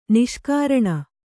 ♪ niṣkāraṇa